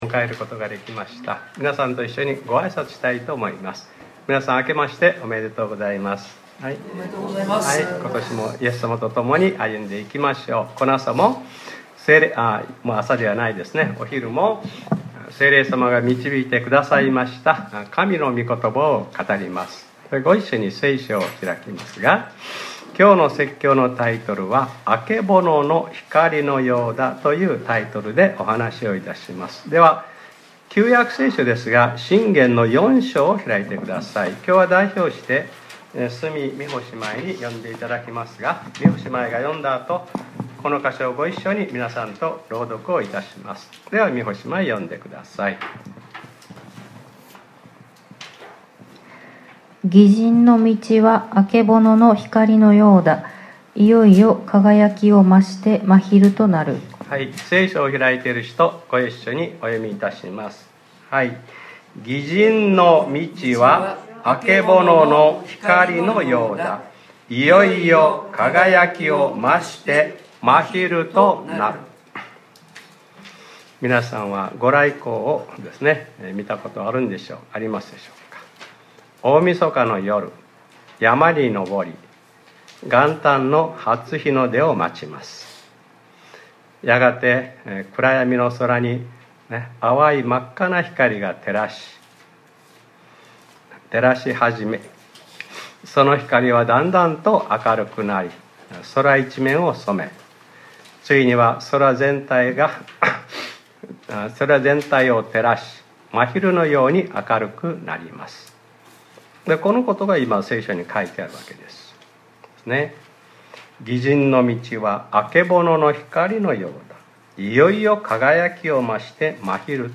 2025年01月01日（日）礼拝説教『 あけぼのの光のようだ 』